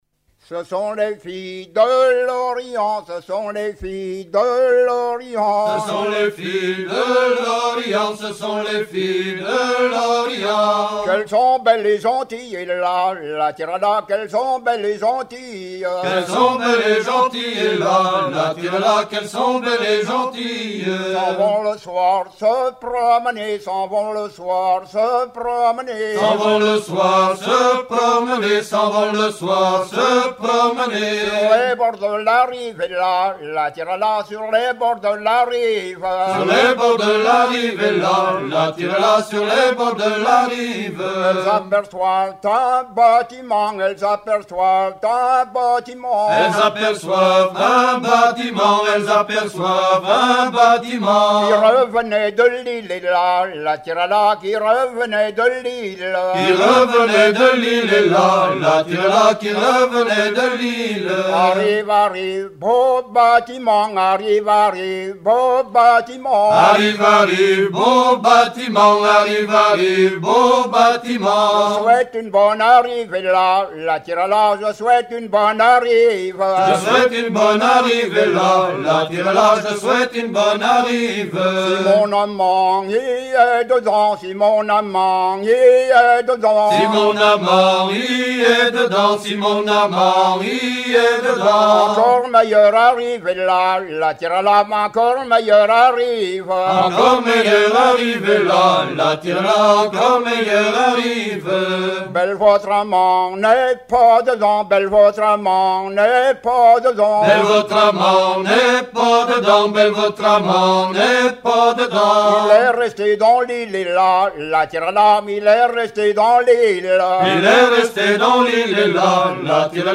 né en 1902 a appris cette chansons dans sa jeunesse à Carnac. collecte de 1982
Genre laisse